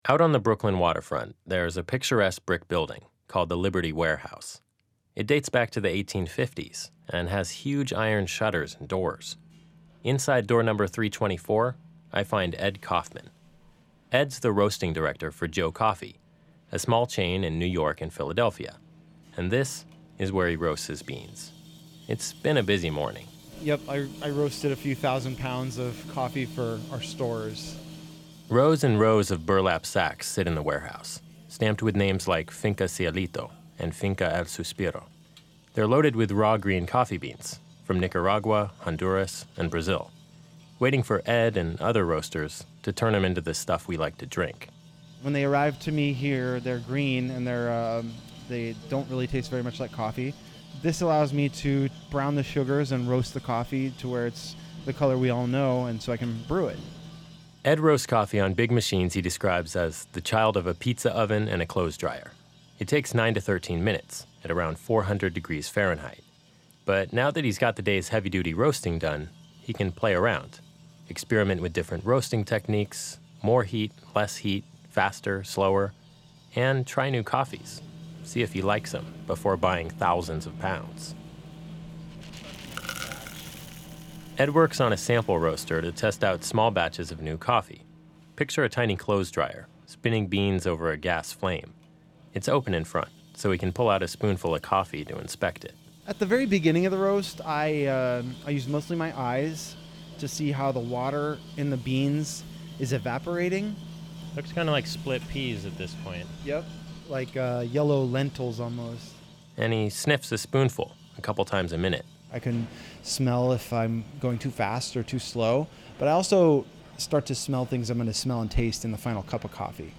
Tiny popping sounds are a roaster’s secret road map.
It’s called the Maillard Reaction and sounds like a bowl of Rice Crispies, but this brief chorus of popping midway through a coffee roast is a crucial link in giving your morning ritual its distict flavor.
coffeeacoustics-new.mp3